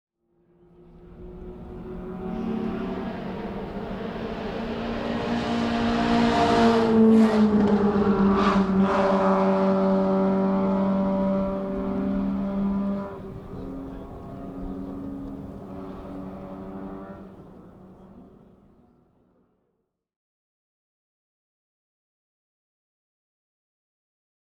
Zu diesem Anlass wird die Strecke in der Sarthe während 45 Minuten für historische Rennfahrzeuge geöffnet, ein Moment, der viele Le Mans Enthusiasten erfreut.
Lotus Elite in Le Mans Legend 2011